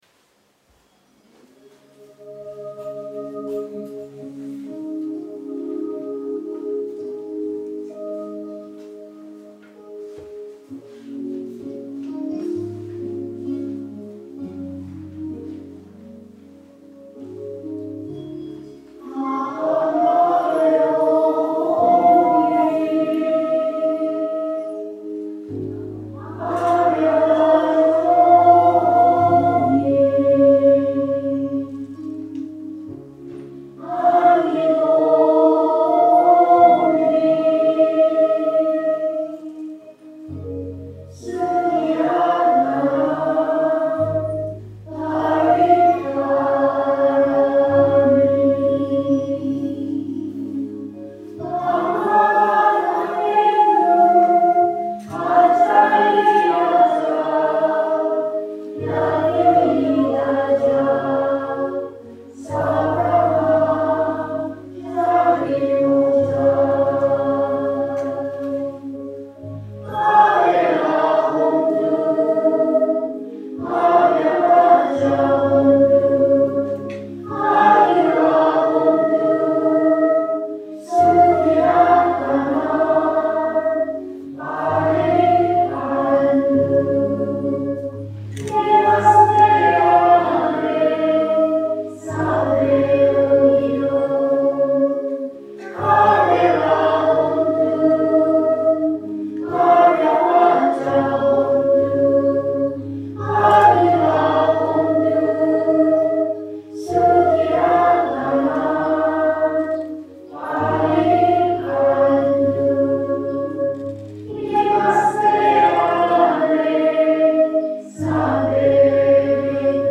불기 2567년 부처님 오신날 봉축 법회_특별공연-1(자애송 가사 첨부, MP3 첨부)